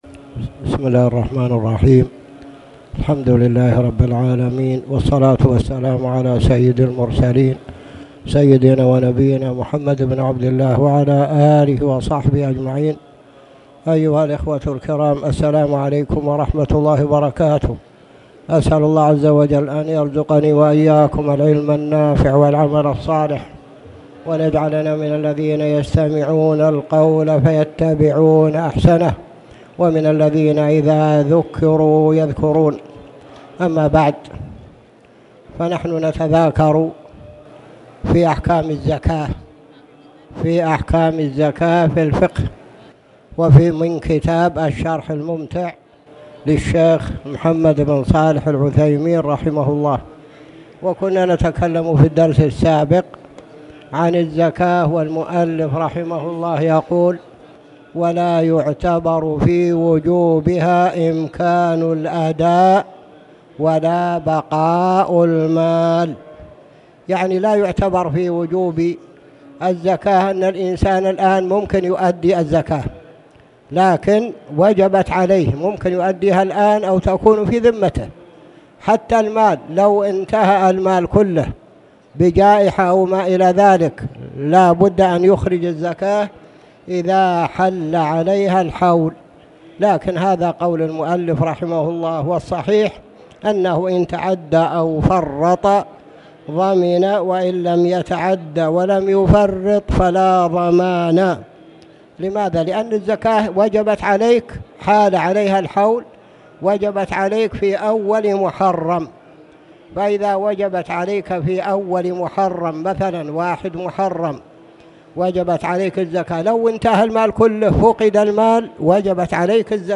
تاريخ النشر ١٢ جمادى الآخرة ١٤٣٨ هـ المكان: المسجد الحرام الشيخ